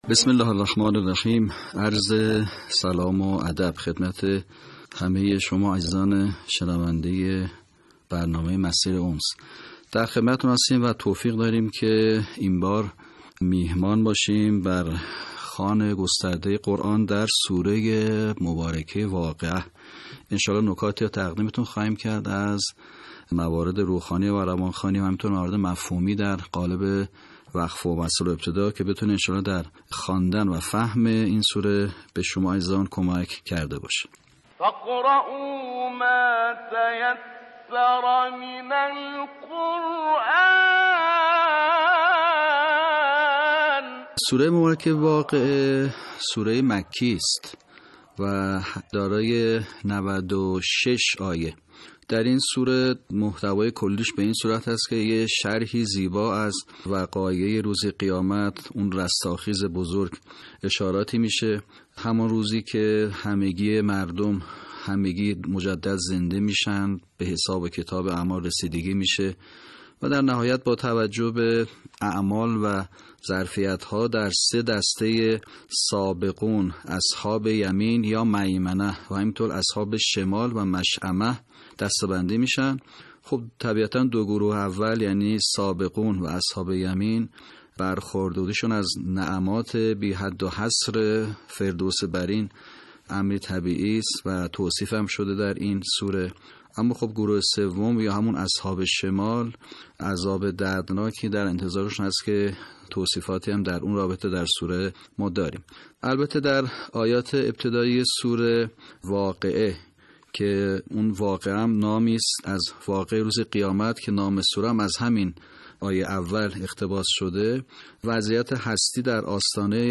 صوت | آموزش صحیح‌خوانی آیات ۱ تا ۱۴ سوره واقعه